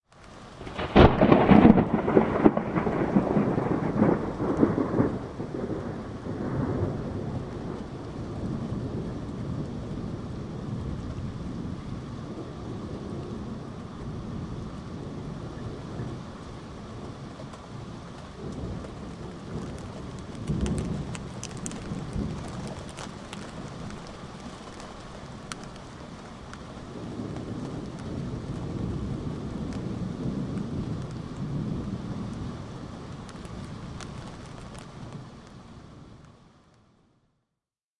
WEATHE R的现场记录 " 00195雷霆13关闭
描述：靠近约。1公里长的雷声和微弱的雨声，由变焦H2的后部
标签： 照明 单射 风暴
声道立体声